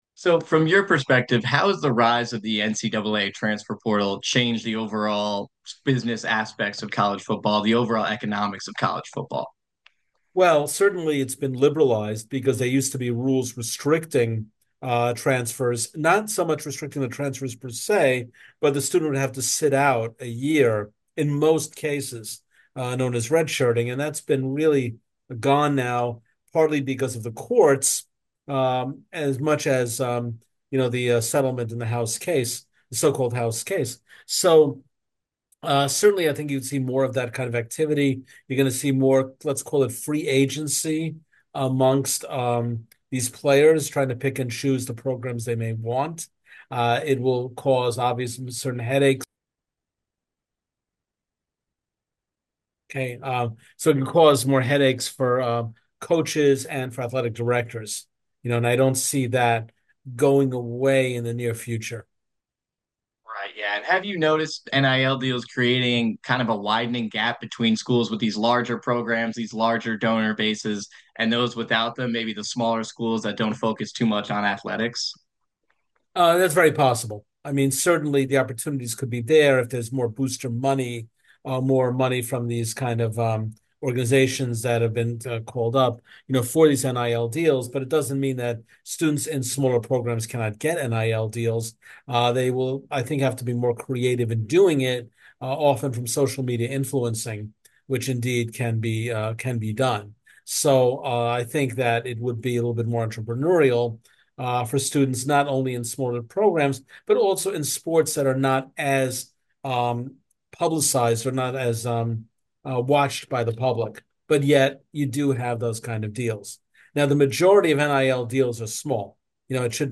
Full 28 Minute Interview